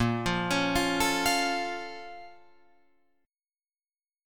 A#mM7 chord